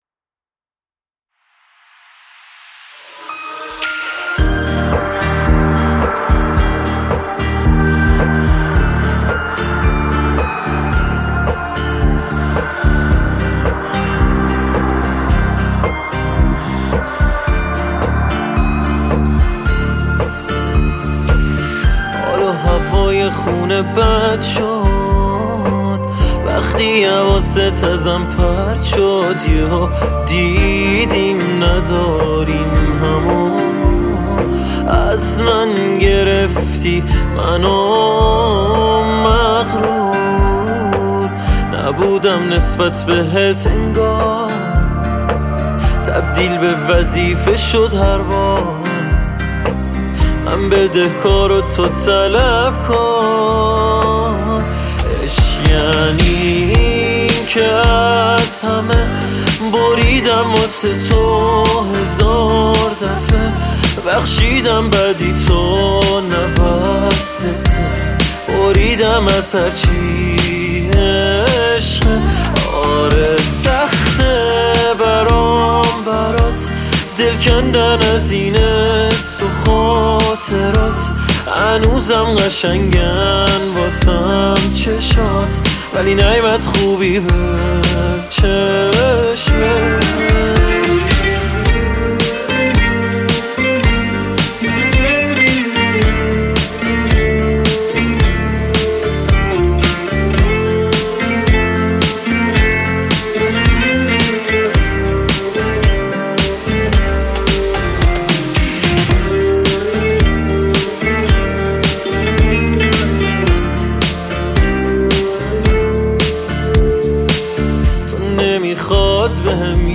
موسیقی
آهنگهای پاپ فارسی